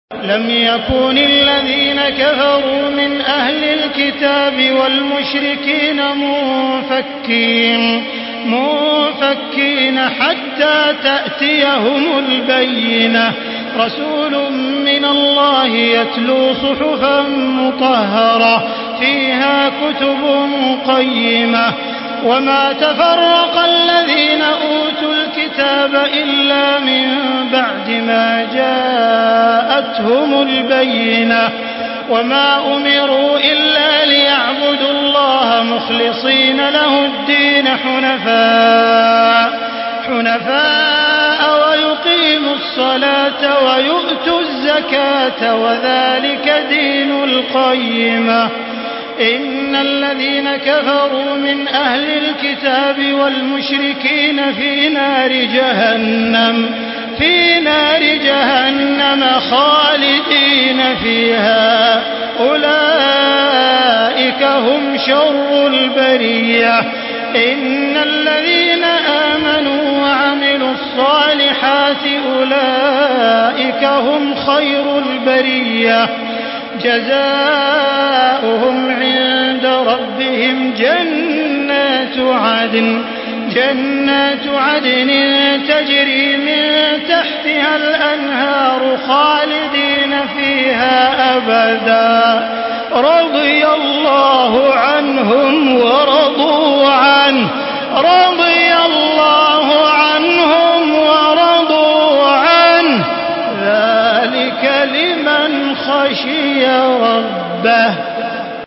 Surah Beyyine MP3 by Makkah Taraweeh 1435 in Hafs An Asim narration.
Murattal